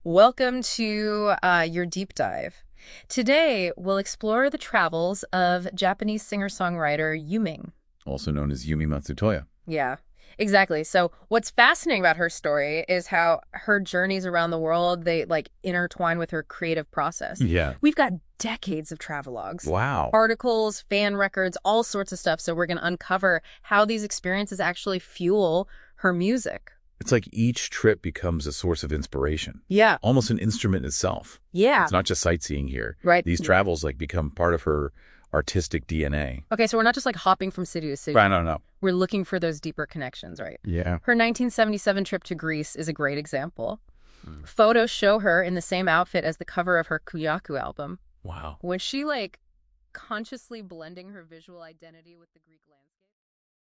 極めつけはユーミンの旅について語る30分の
トークショー（冒頭1分ほどにカットしています）。